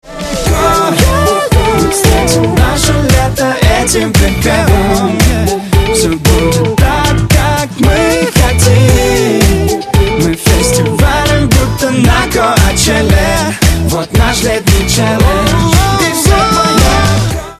• Качество: 128, Stereo
dance